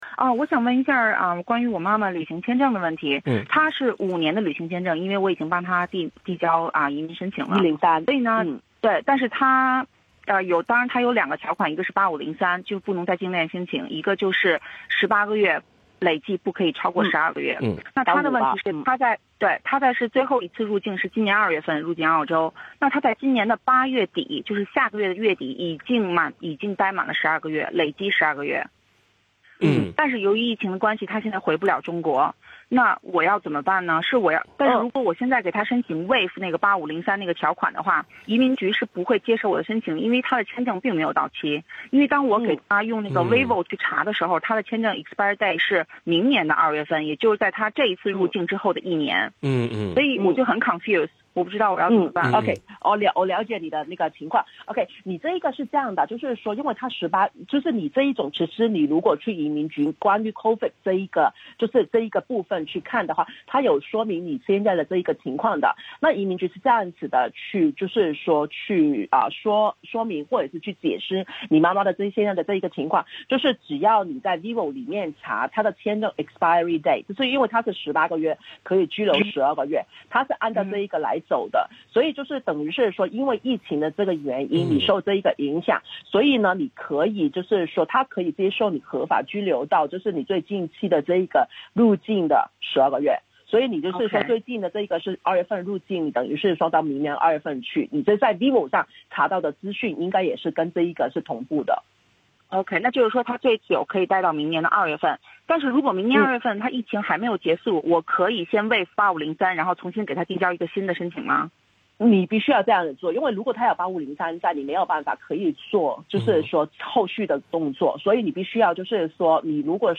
热线节目
legal_talkback_8503_conditions.mp3